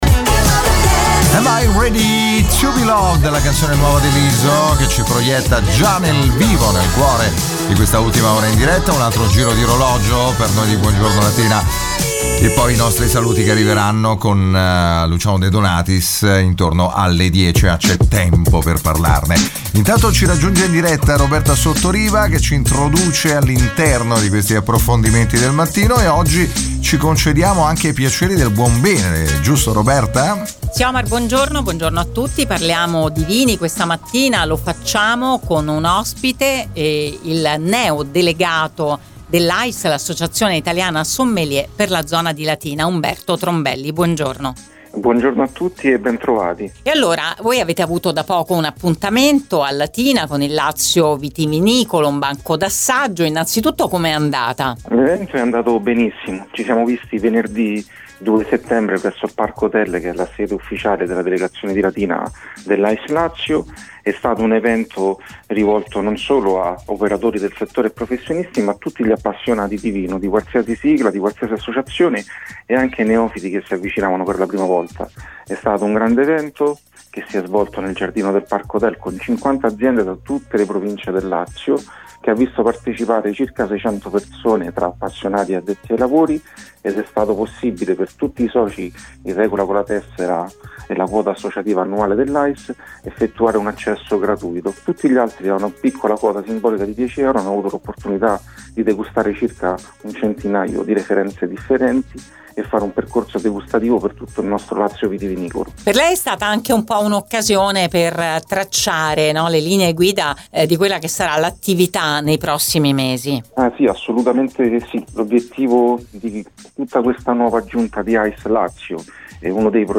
Ne abbiamo parlato con lui su Radio Immagine